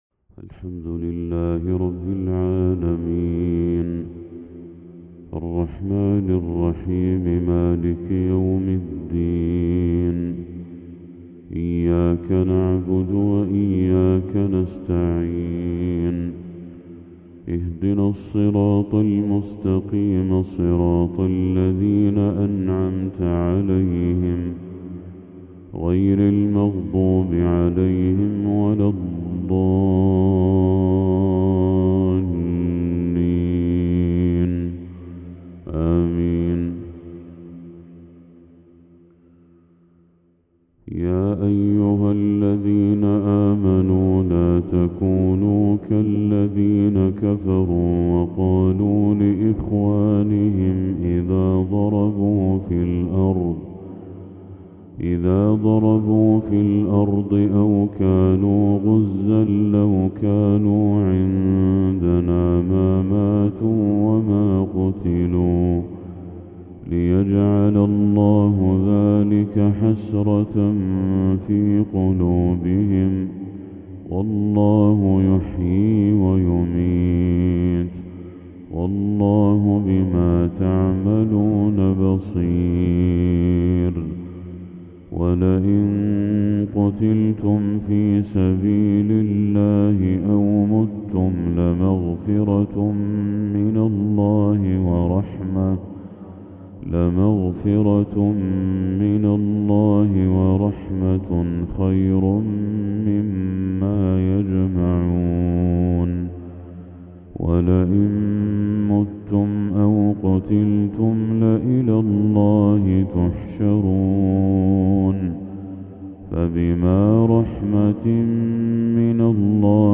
تلاوة من سورة آل عمران للشيخ بدر التركي | فجر 29 ذو الحجة 1445هـ > 1445هـ > تلاوات الشيخ بدر التركي > المزيد - تلاوات الحرمين